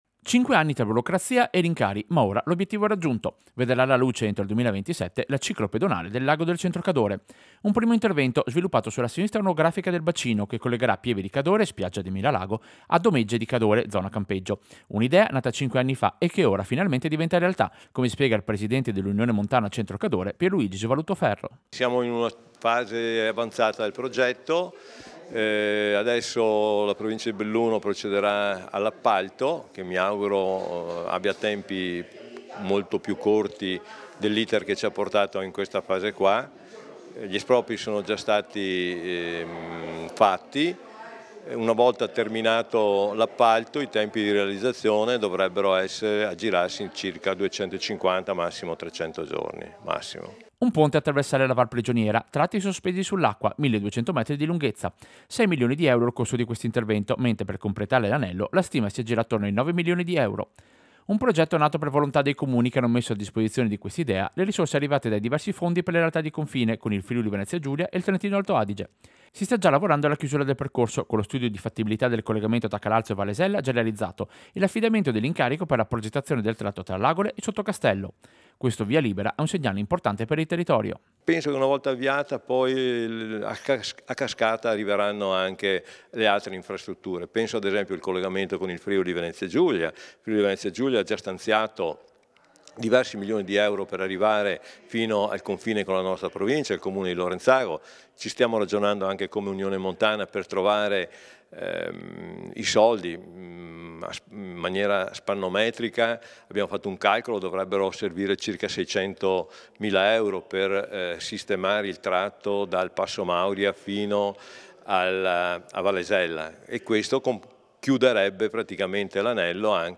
Servizio-Ciclopedonale-Lago-Centro-Cadore.mp3